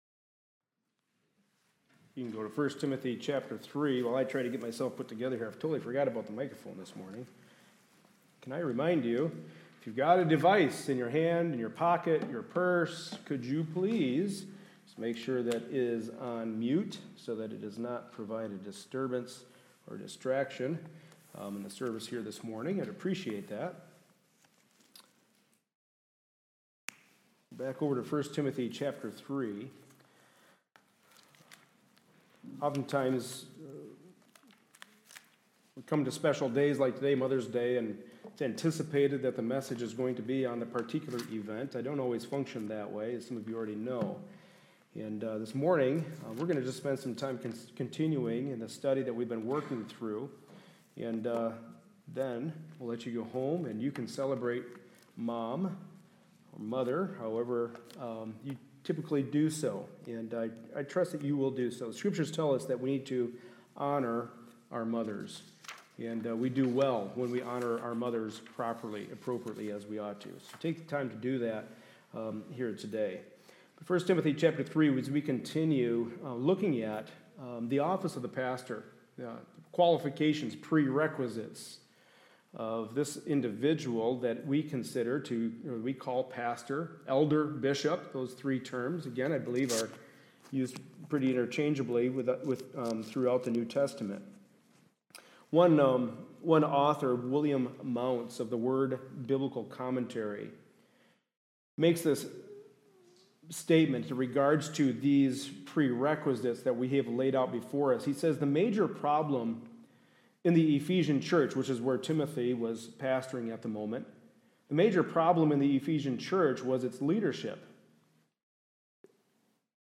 1 Timothy 3:1-7 Service Type: Sunday Morning Service A study in the pastoral epistles.